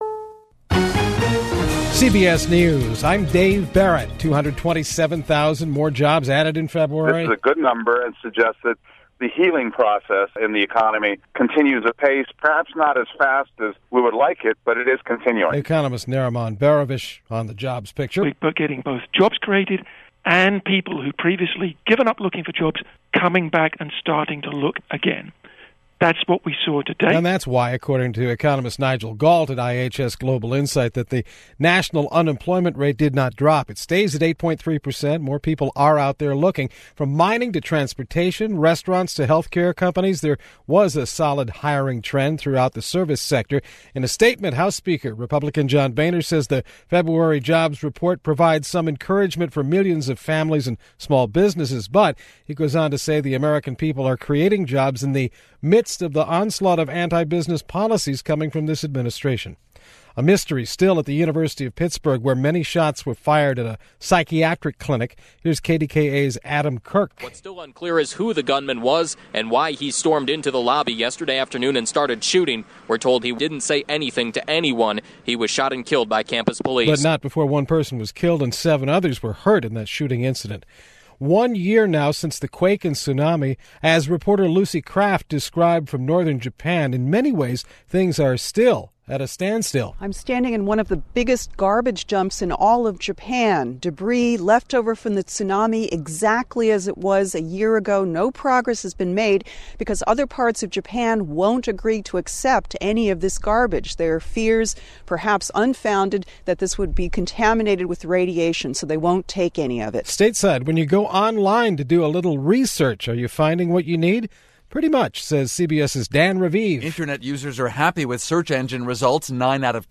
CBS Radio News 10:00aes newscast (3/9/12)